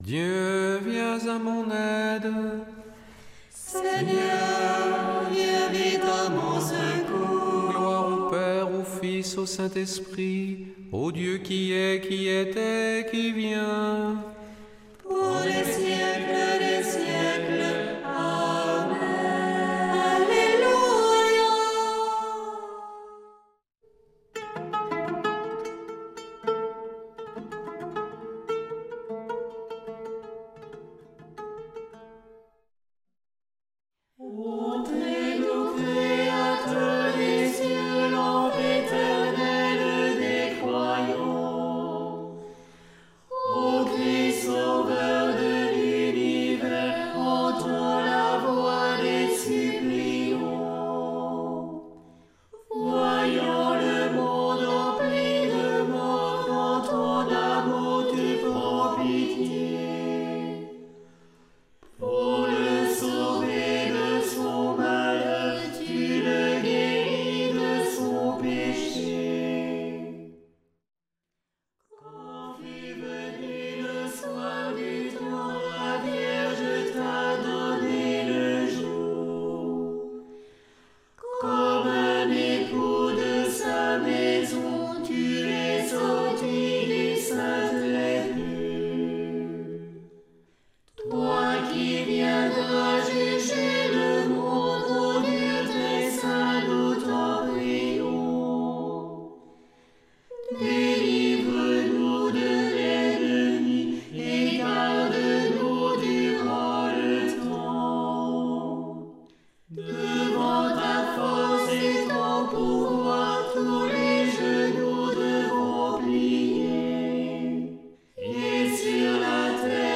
Prière du soir
Une émission présentée par Groupes de prière